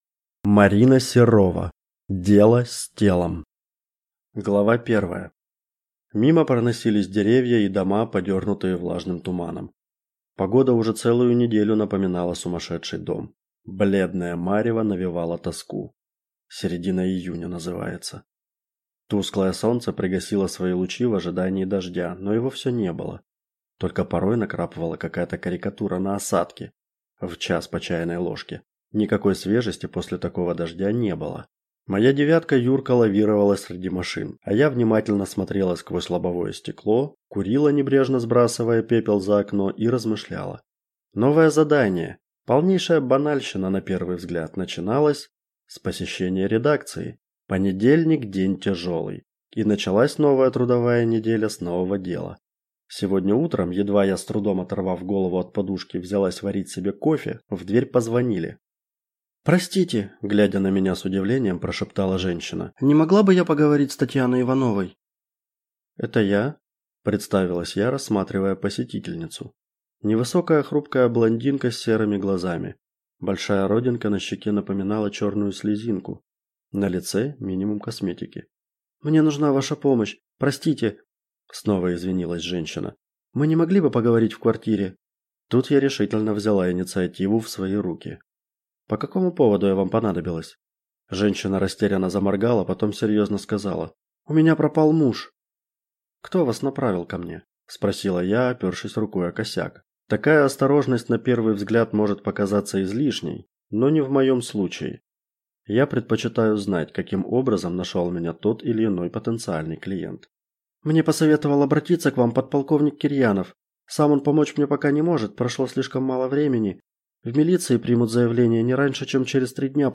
Aудиокнига Дело с телом